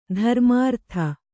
♪ dharmārtha